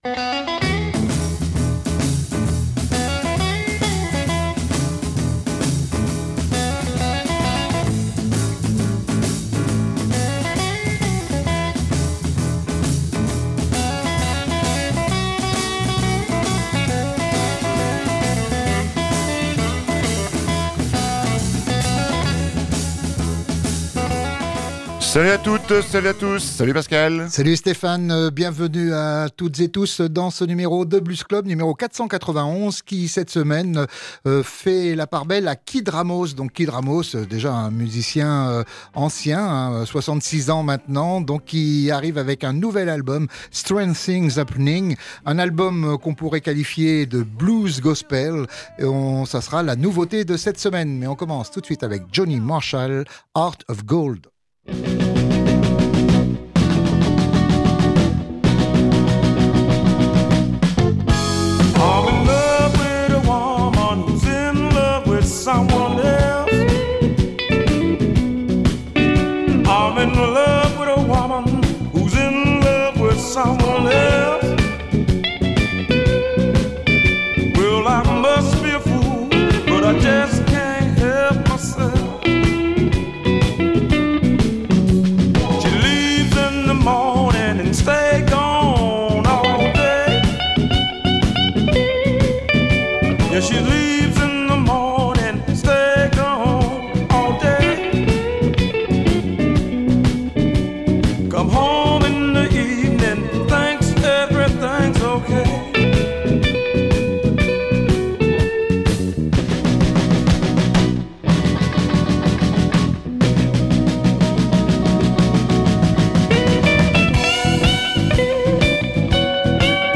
Il s’agit d’un disque plutôt inattendu de la part de cet artiste, car il compte douze chansons très inspirées du gospel!